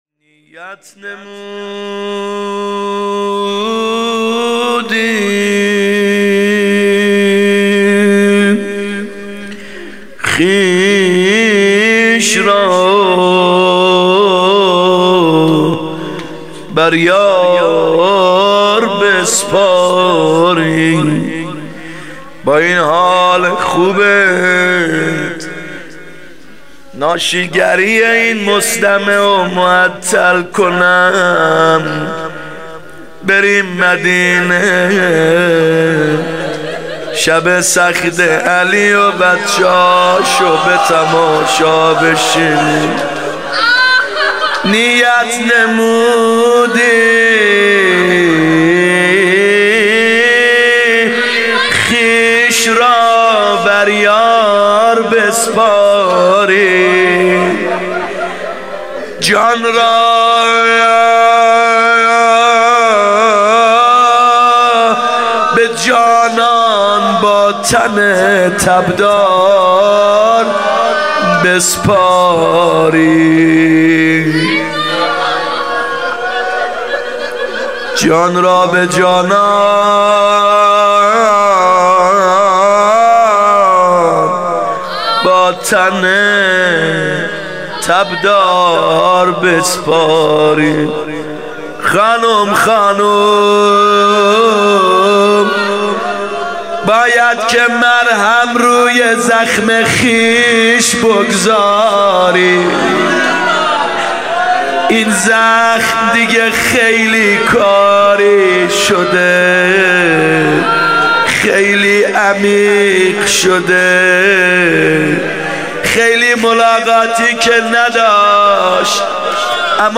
فاطمیه هیات یامهدی عج